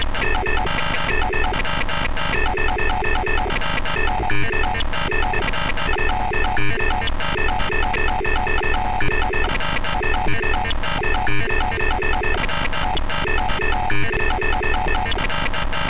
Printer-like Machine noise
device electronic machine printer sound effect free sound royalty free Memes